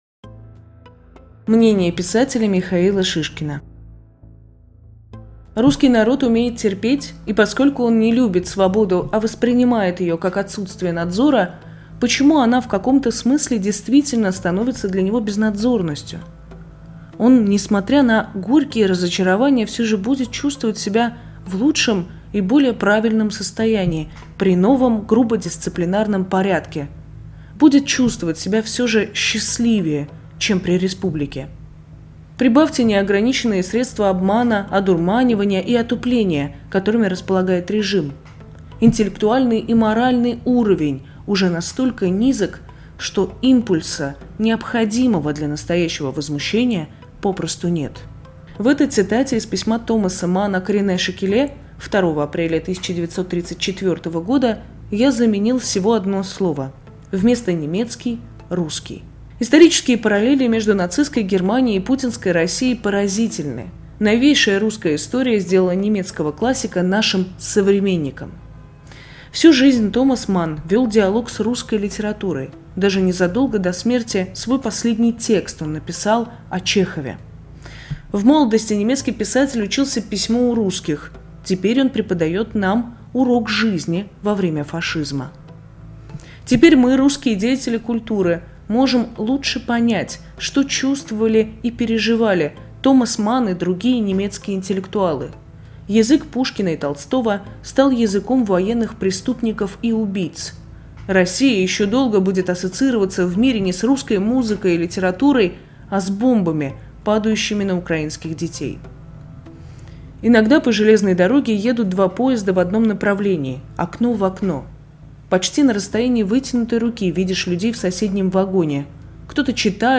Мнение писателя Михаила Шишкина